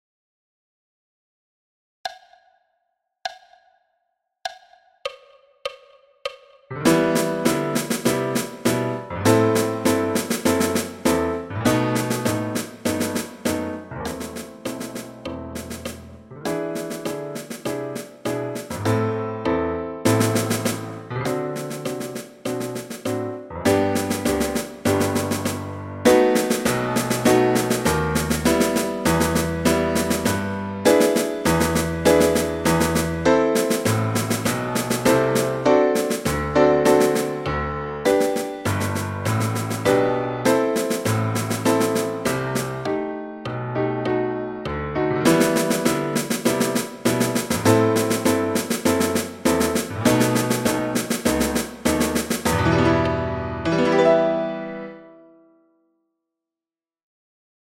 Etude n°5 – Londeix – caisse et piano à 100 bpm